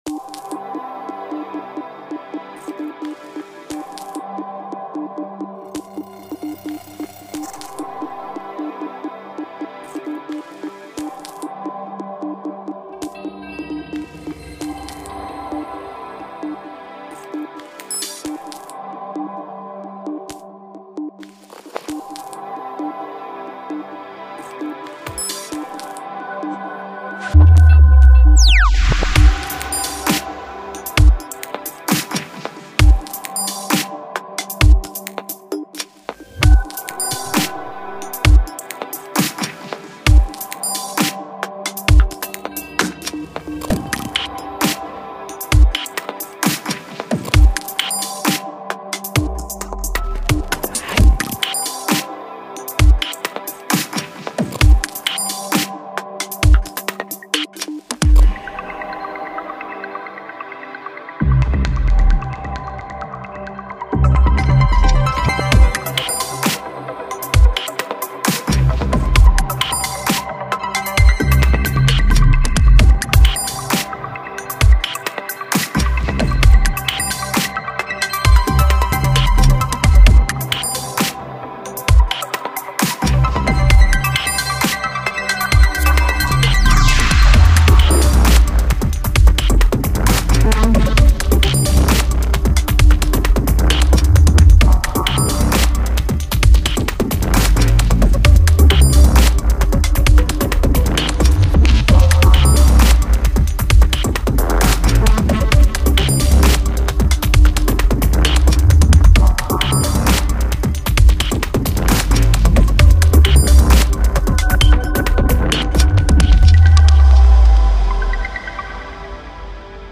Dubstep (soft Cubase)